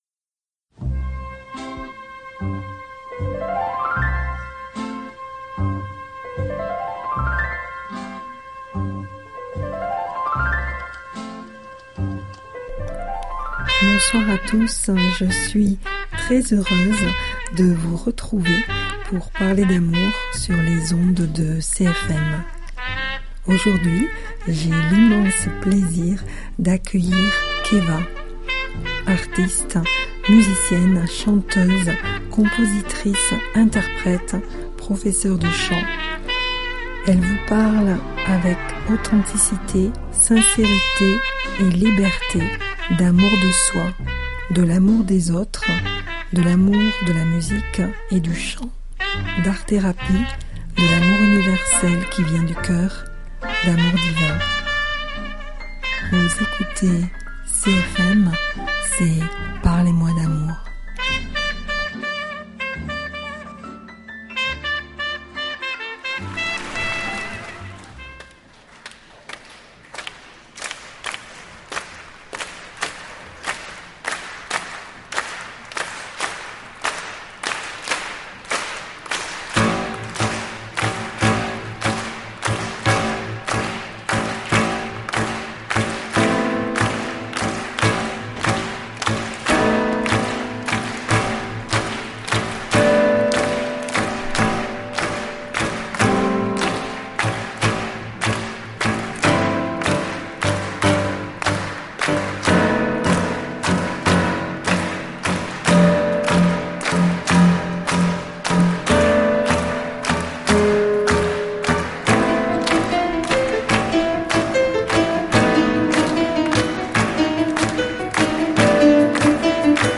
chanteuse, musicienne et professeure de chant